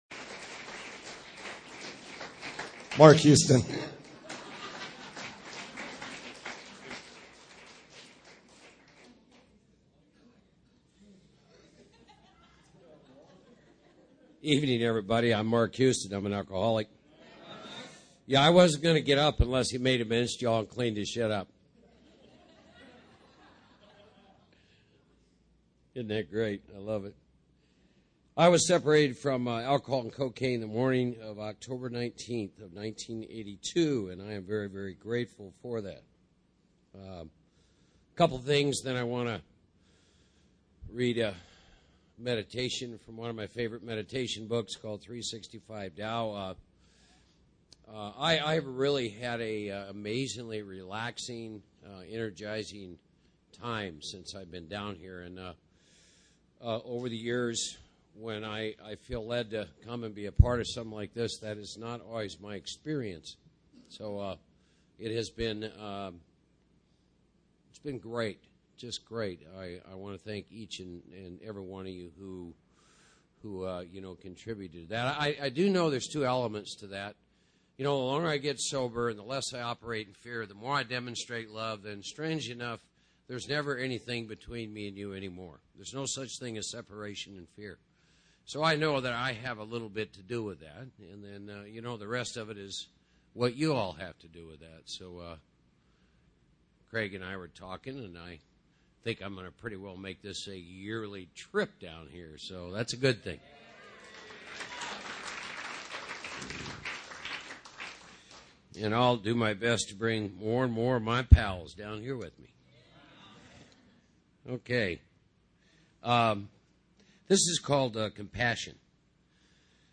Big Book Workshop in Cabo San Lucas, Mexico